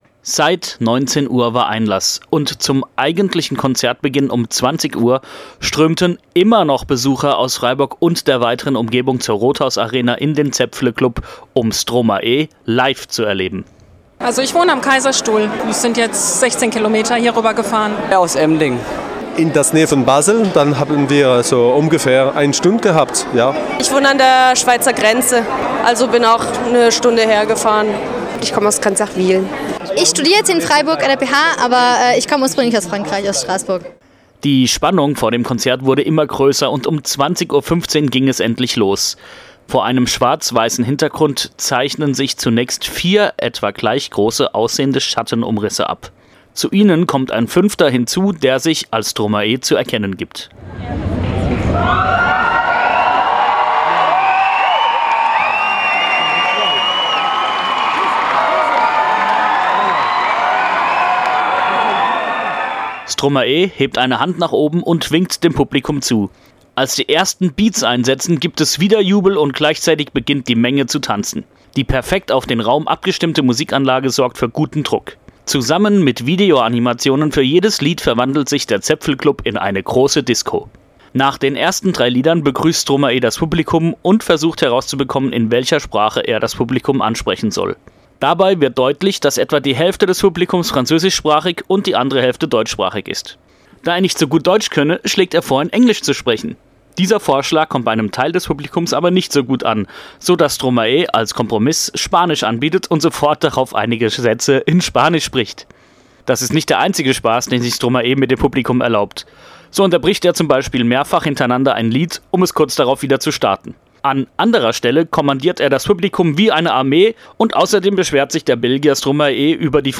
Gebauter Beitrag